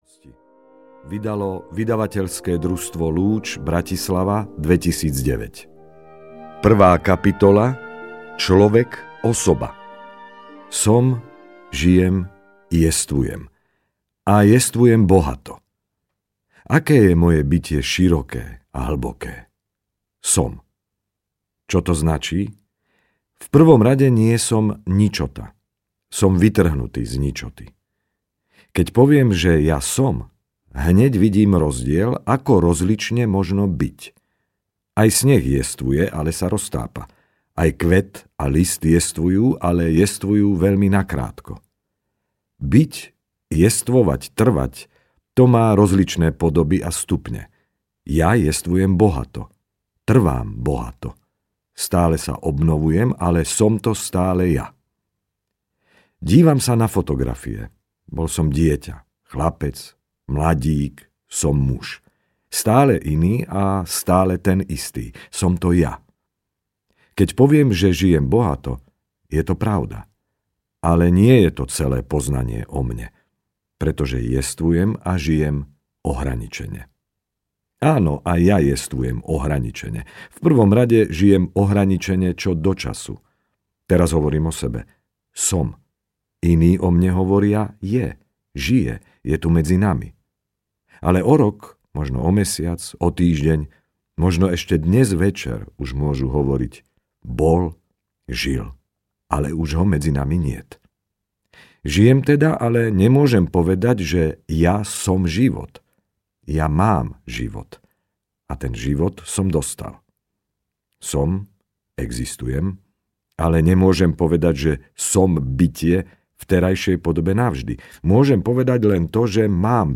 Rozvoj kresťanskej osobnosti audiokniha
Ukázka z knihy